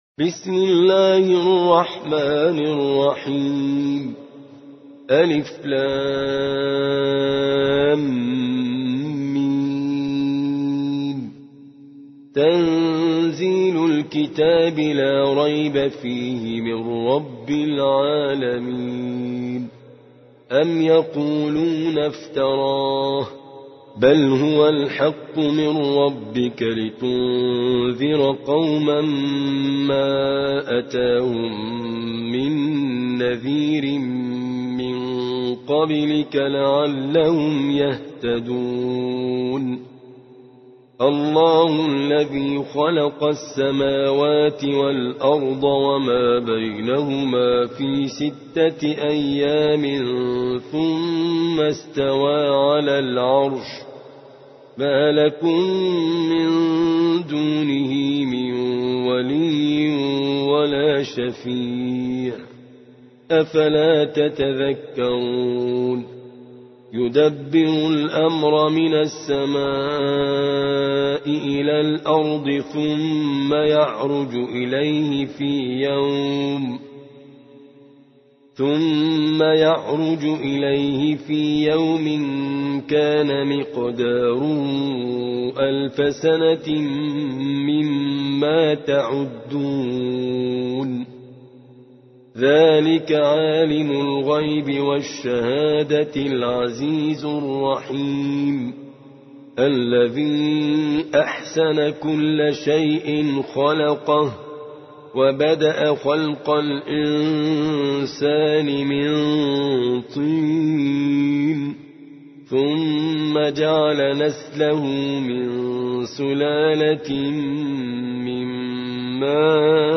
32. سورة السجدة / القارئ